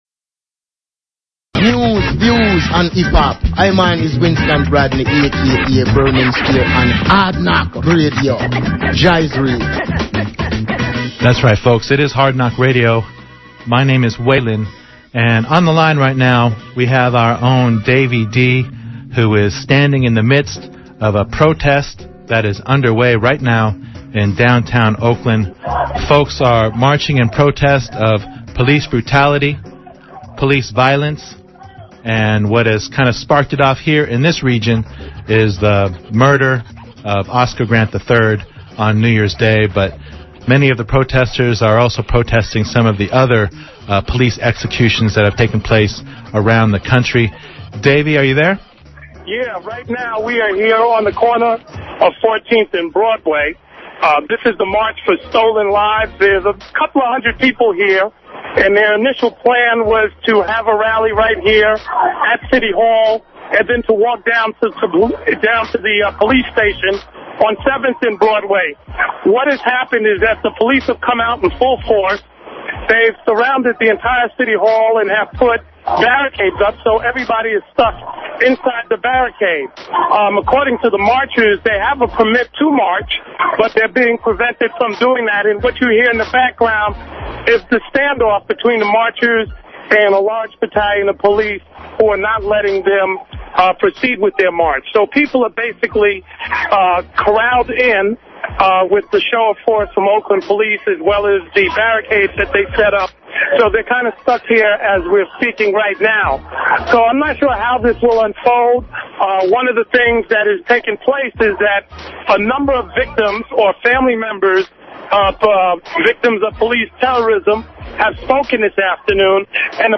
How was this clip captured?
at March Of Stolen Lives